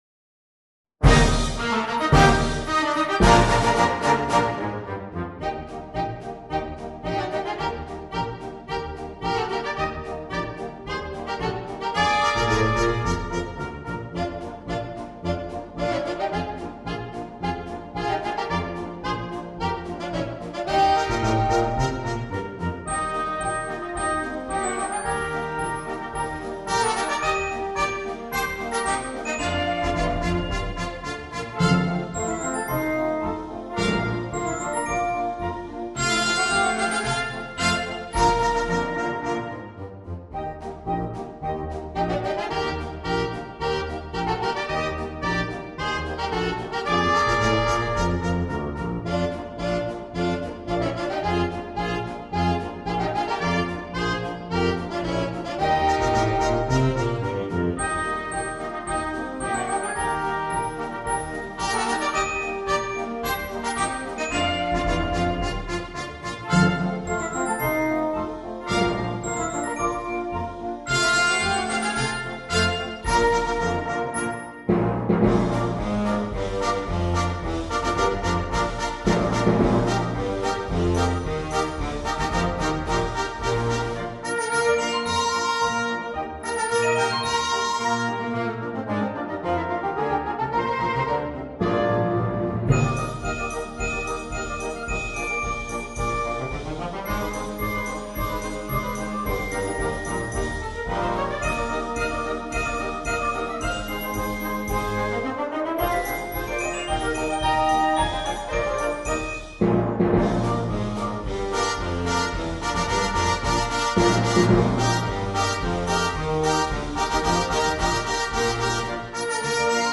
MUSICA PER BANDA